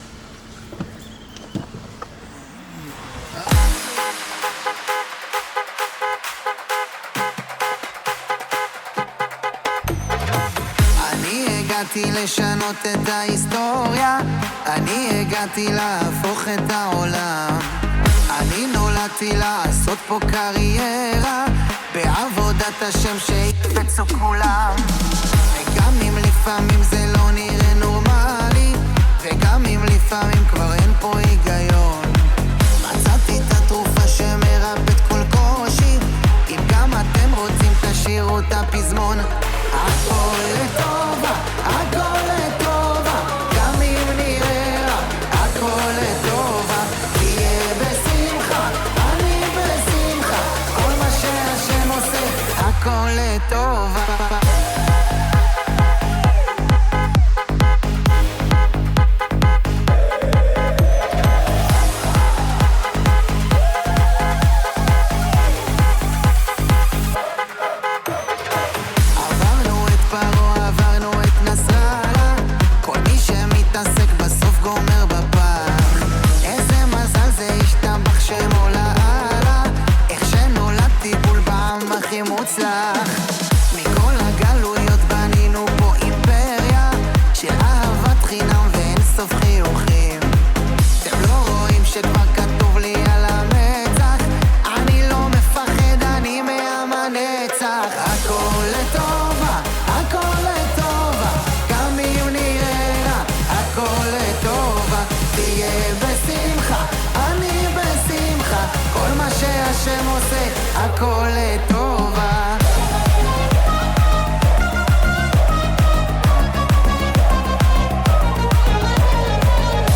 דואט מרגש